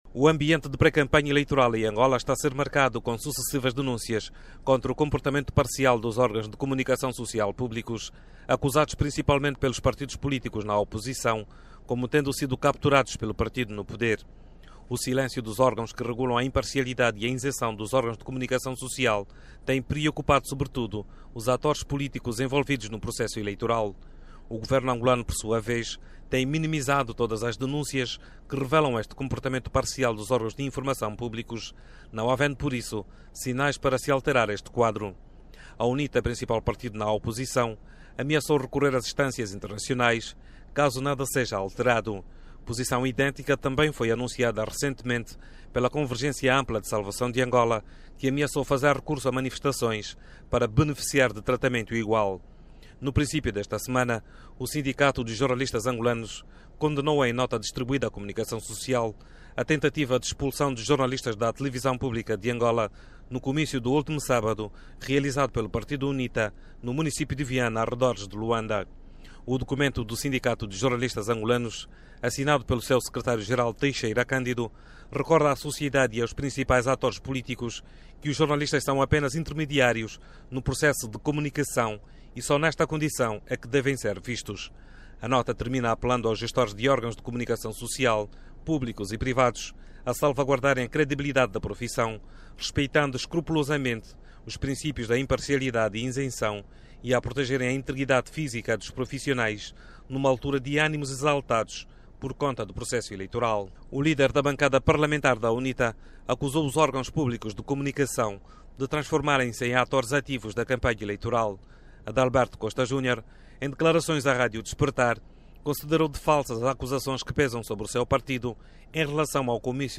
Partidos políticos na oposição e membros da sociedade civil acusam os órgãos de comunicação social públicos como estando a assumir o papel de principais actores do ambiente de pré campanha eleitoral que Angola está a viver. Para falar sobre o assunto, ouvimos o líder da bancada parlamentar da...